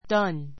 done 中 A2 dʌ́n ダ ン 動詞 do の過去分詞 do I have already done my homework.